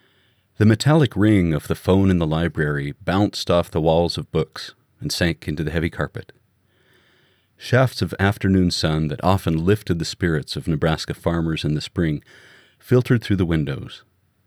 These are from my first audiobook, which I’m working on for ACX.
I feel like the processed cut is coming out sounding a bit tinny or hollow, and I realized that I just don’t have enough depth of experience to know what I’m listening for.
I’m using a Blue Nessie mic, Windows 10, Audacity 2.3.2. My booth is an inside closet with some blankets hanging down. I’m a little concerned about noise floor, since I have the laptop fan is usually going. It’s quiet, but constant.
You have a very nice voice that’s pleasant to listen to and the content sounds interesting.